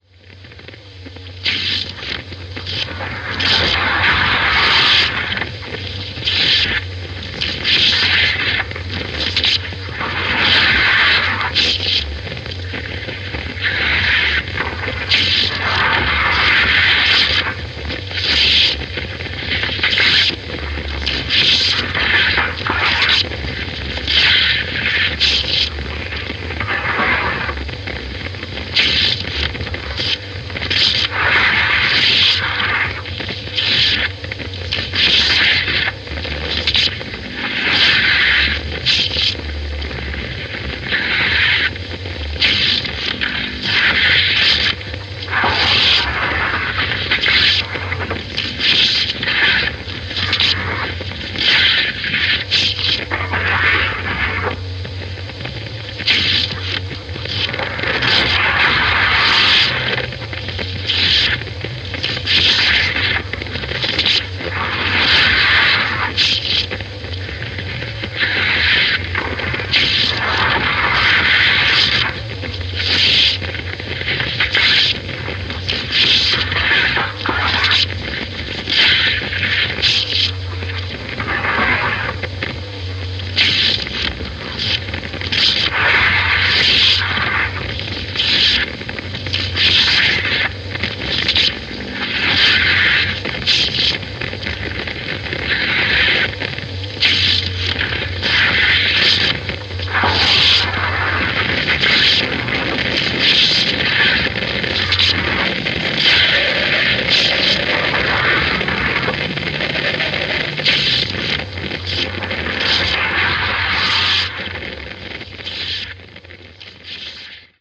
modified transistor radio
modified turntable
modified magnetic tape